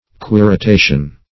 (kw[i^]r`[i^]*t[=a]"sh[u^]n)